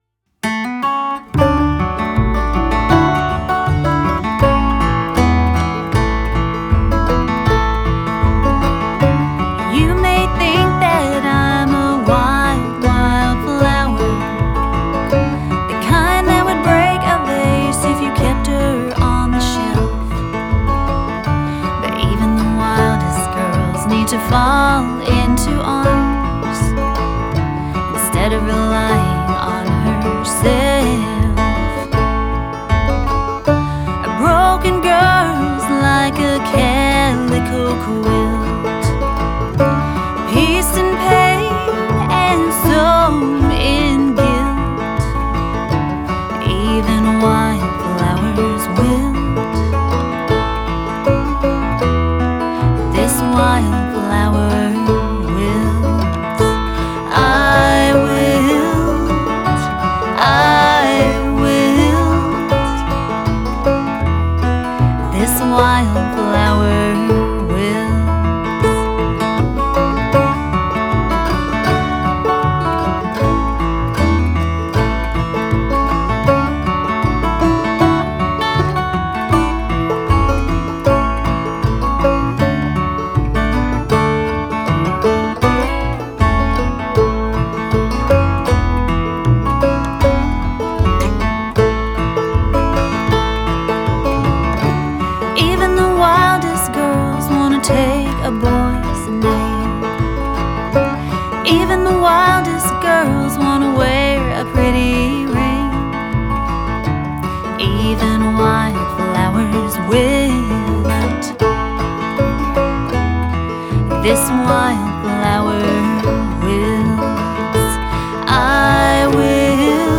solo album
vocals, bass, autoharp
fiddle, guitar, harmony vocals